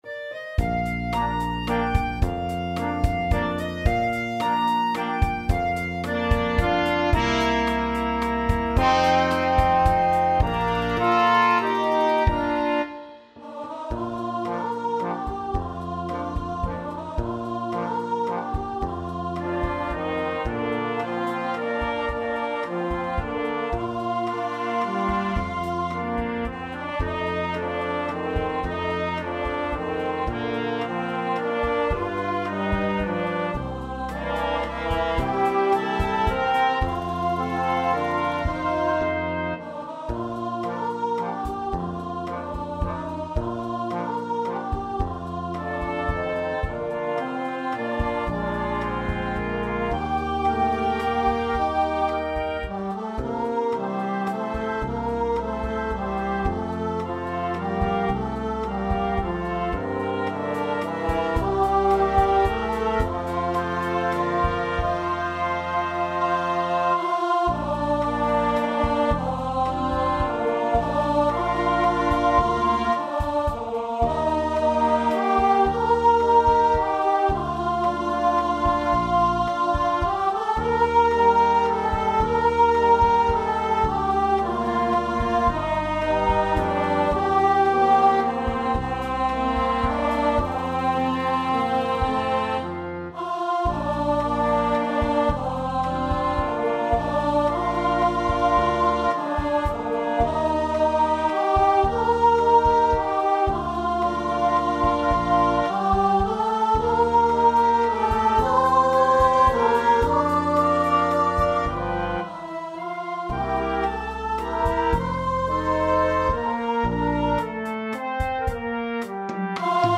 Free Sheet music for Flexible - 4 Players and Rhythm Section
ClarinetAlto Saxophone
TrumpetBaritone Horn
Trombone
Piano
DrumsGuitarBass Guitar
Bb major (Sounding Pitch) (View more Bb major Music for Flexible - 4 Players and Rhythm Section )
3/4 (View more 3/4 Music)
Valse moderato espressivo = 110
Pop (View more Pop Flexible - 4 Players and Rhythm Section Music)